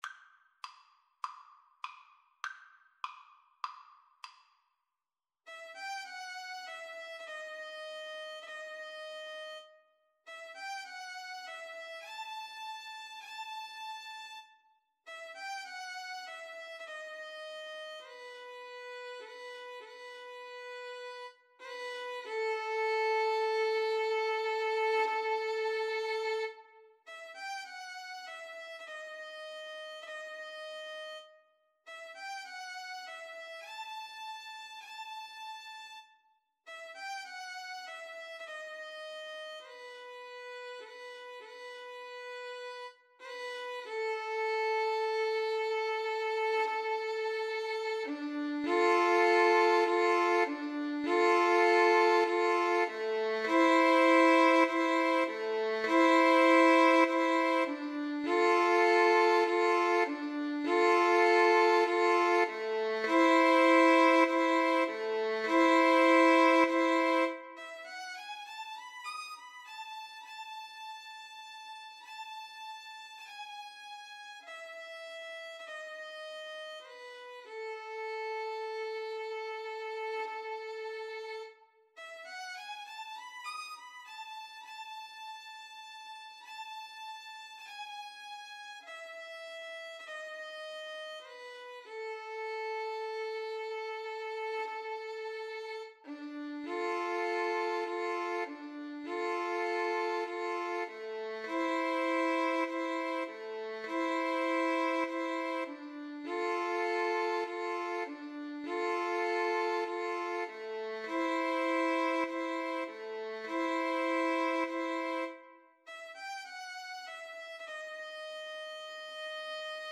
Free Sheet music for Violin-Cello Duet
A minor (Sounding Pitch) (View more A minor Music for Violin-Cello Duet )
Classical (View more Classical Violin-Cello Duet Music)